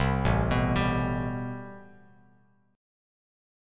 Am9 Chord
Listen to Am9 strummed